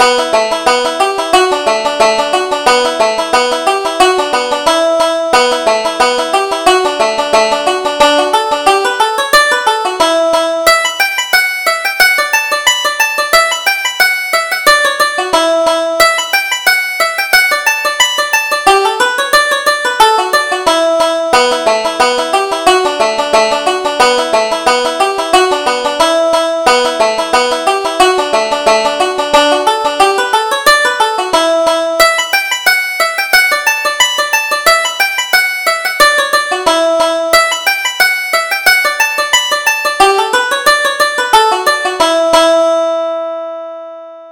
Reel: Miss Patterson